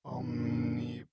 khanat-sounds-sources/_stock/sound_library/voices/prayers/initiate_build1.wav at b47298e59bc2d07382d075ea6095eeaaa149284c